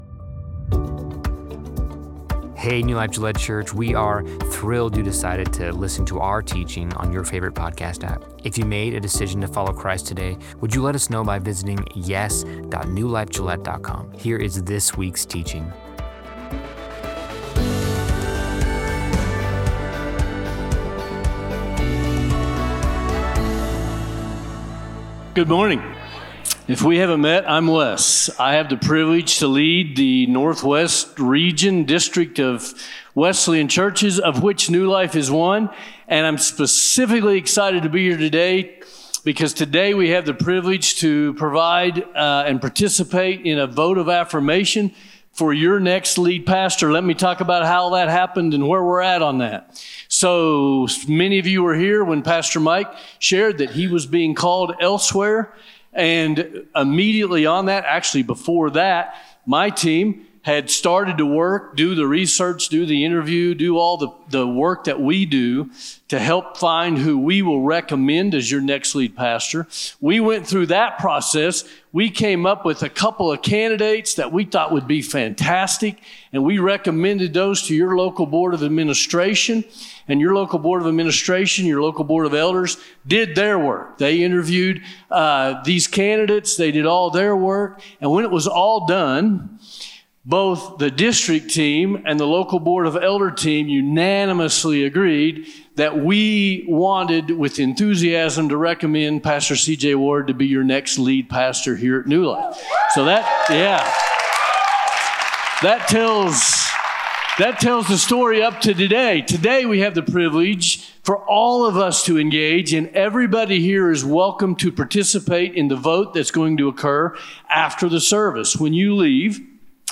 New Life Gillette